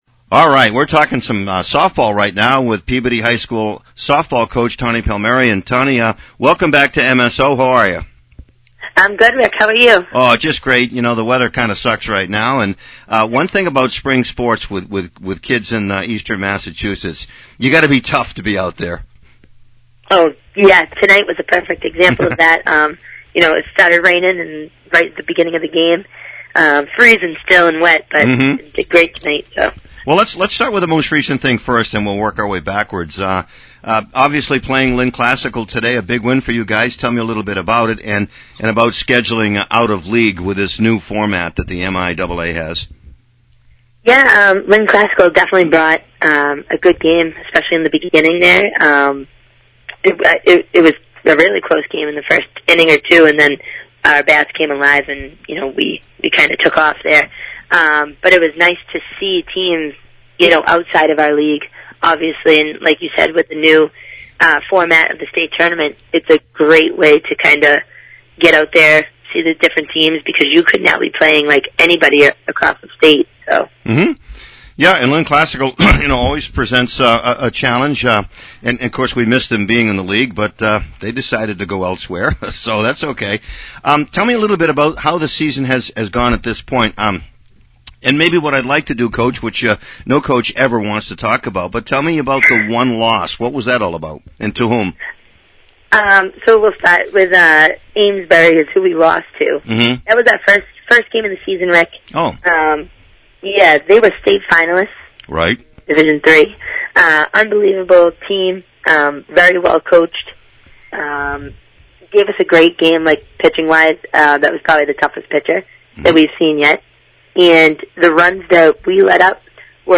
(Audio) Post-game, Pre-game: